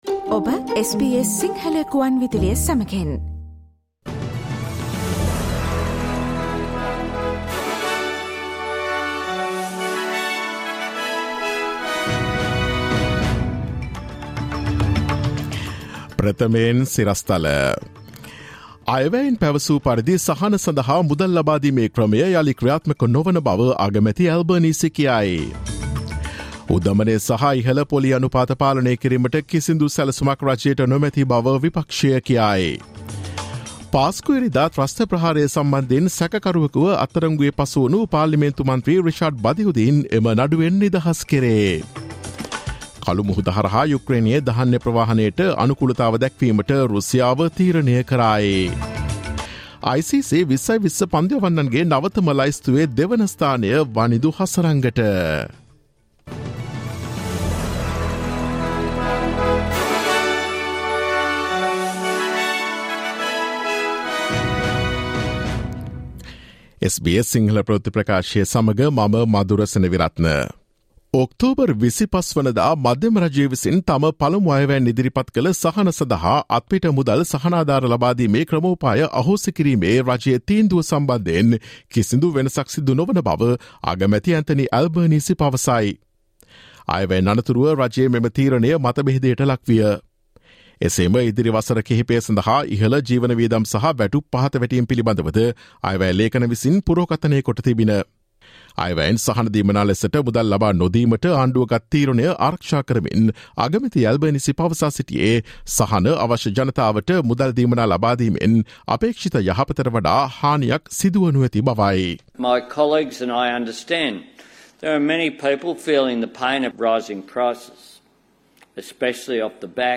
Listen to the SBS Sinhala Radio news bulletin on Thursday 03 November 2022